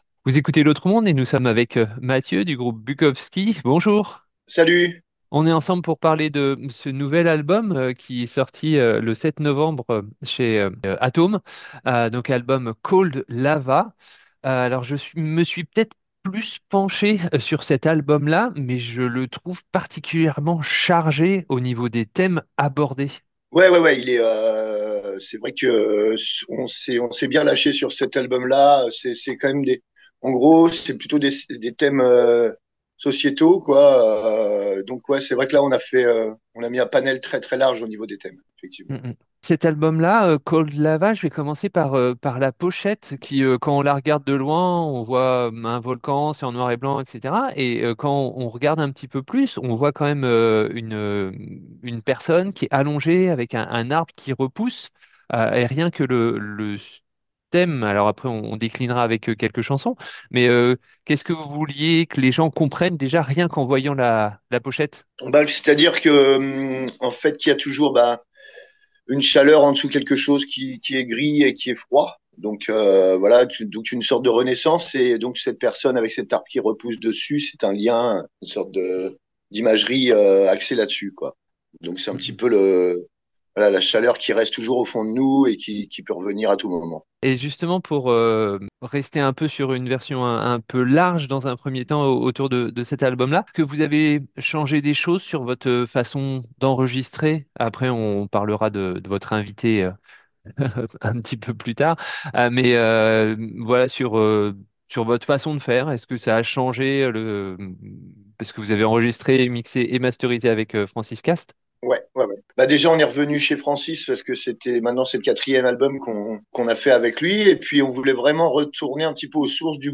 BUKOWSKI - 19.11.2025 - itw
interview